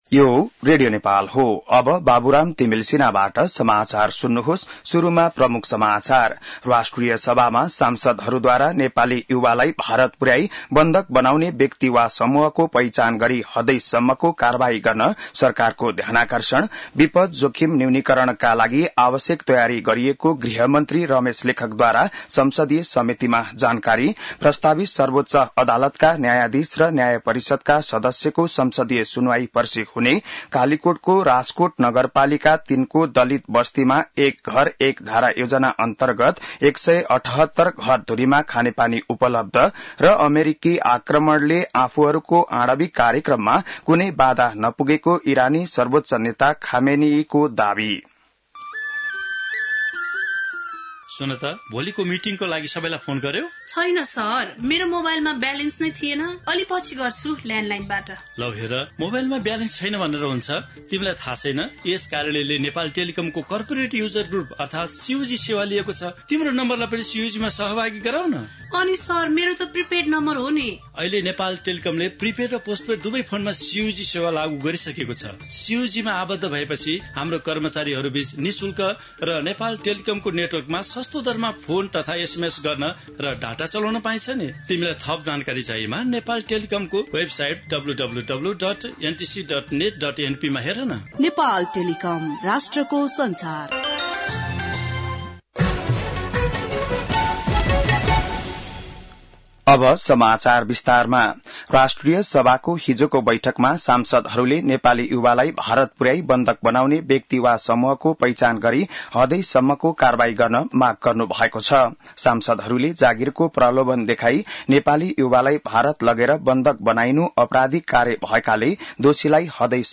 बिहान ७ बजेको नेपाली समाचार : १३ असार , २०८२